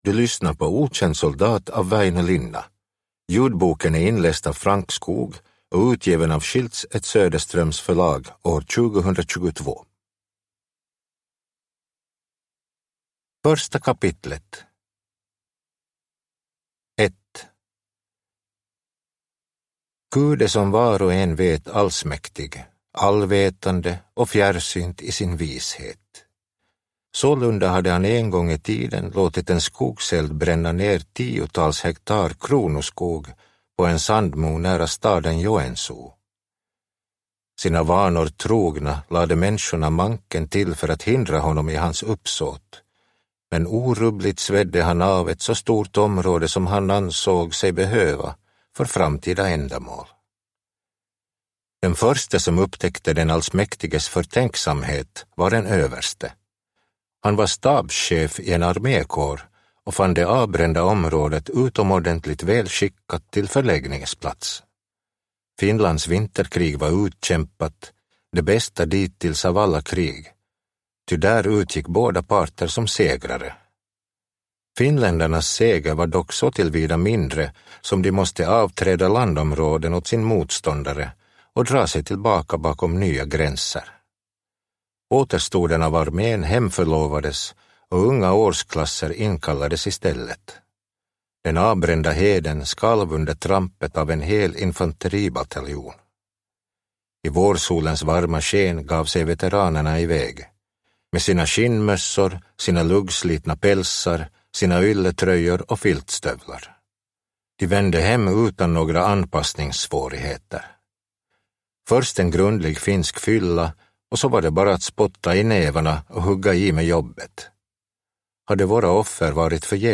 Okänd soldat – Ljudbok – Laddas ner